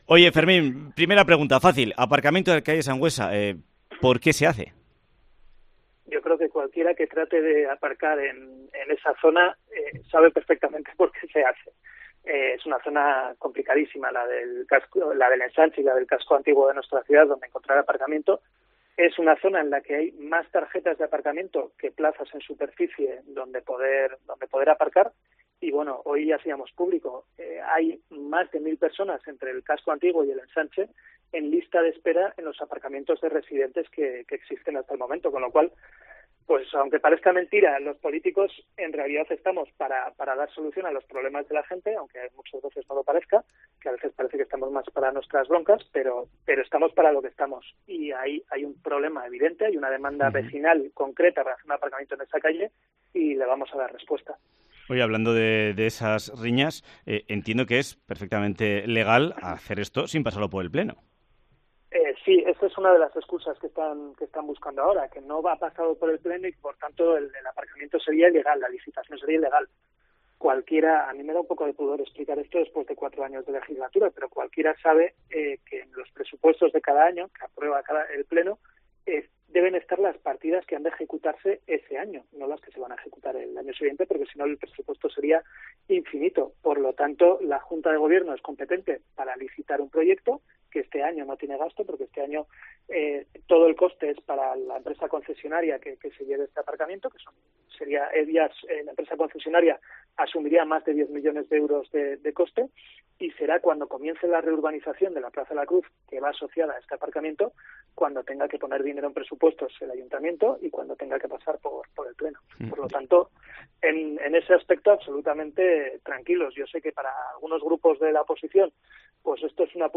Entrevista a Fermín Alonso sobre los aparcamientos de la calle Sangüesa
Fermín Alonso, concejal del Ayuntamiento de Pamplona, nos presenta los motivos y los beneficios de las 346 plazas de aparcamiento en la calle Sangüesa de Pamplona